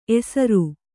♪ esaru